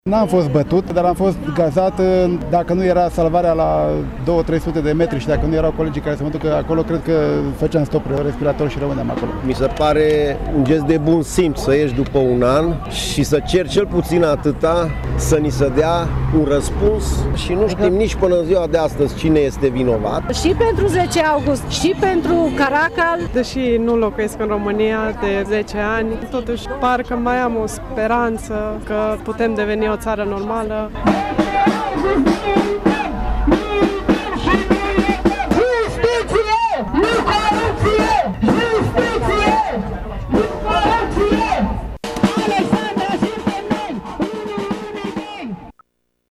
Protestul de la Tg. Mureș a început la ora 19.00 și s-a încheiat la ora 20.30, între timp oamenii au făcut un tur de centru al orașului scandând pentru justiție și împotriva corupției: